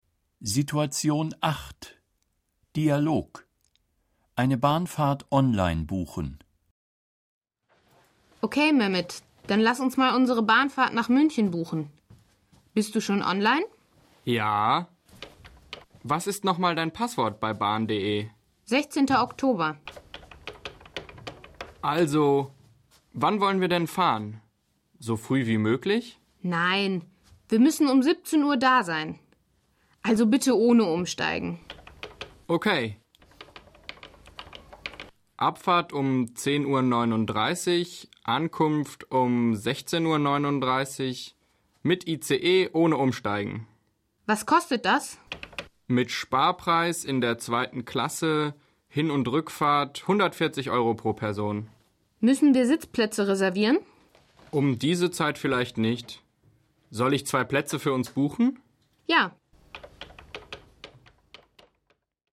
Situation 8 – Dialog: Eine Bahnfahrt online buchen (998.0K)